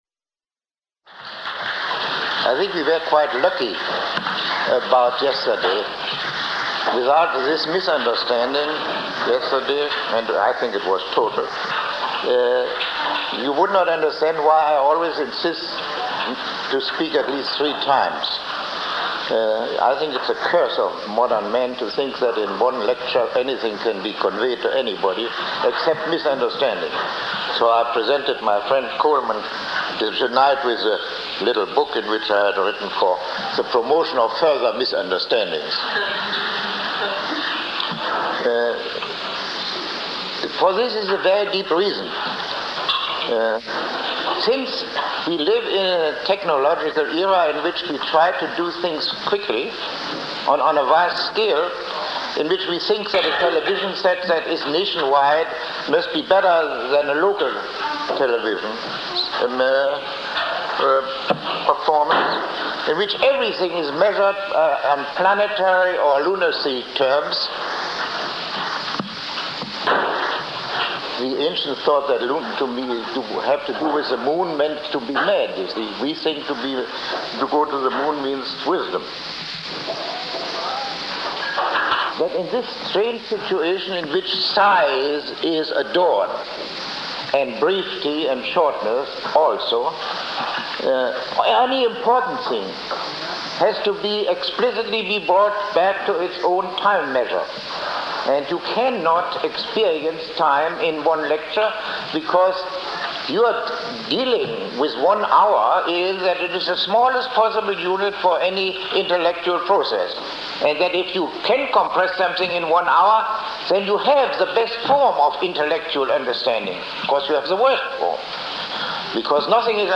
Lecture 03